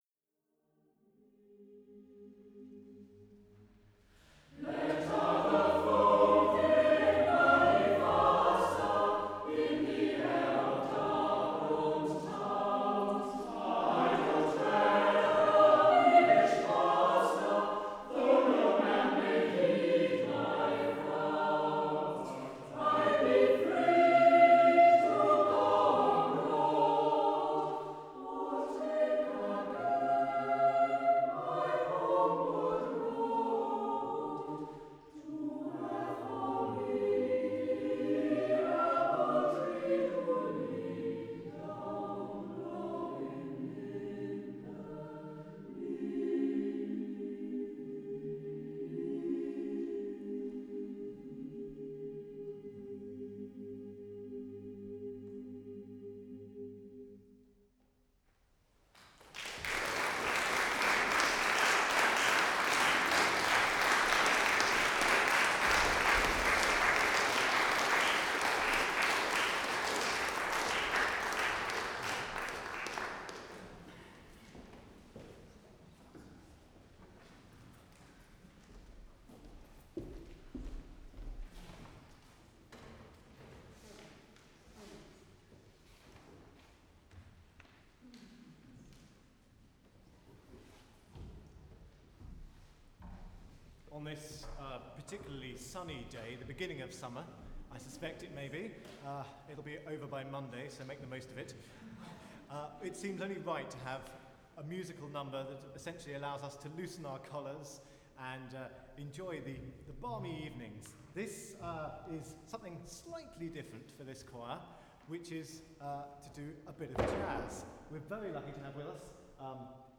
This recording was made hours after I received my Core Sound TetraMic, with no opportunity even to check balance. You hear the end of Vaughan Williams's arrangement of Linden Lea, applause, an announcement, laughter, and the start of Rutter's Birthday Madrigals - sung by the Cherwell Singers in the Holywell Music Room, Oxford (historic, but not actually a particularly good recording venue).
Ambisonic
Ambisonic order: F (4 ch) 1st order 3D
Microphone name: Core Sound TetraMic
Array type: Tetrahedral
Capsule type: Cardioid electret